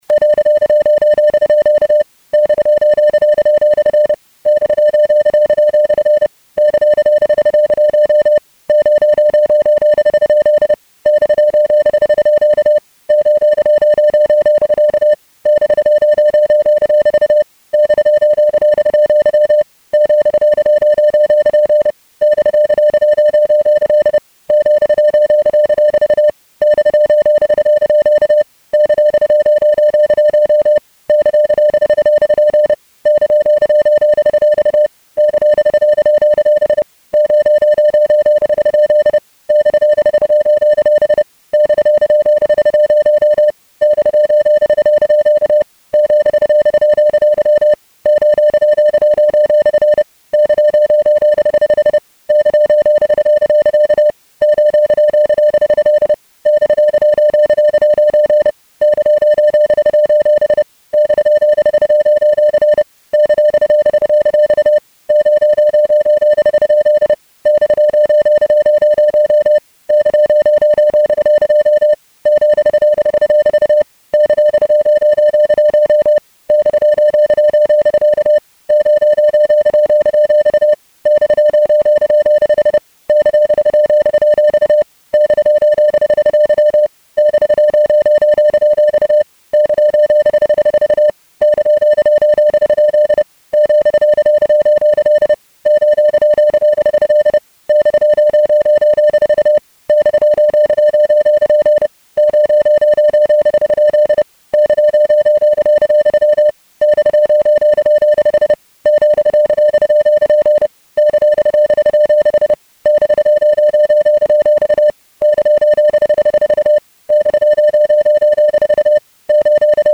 电码.mp3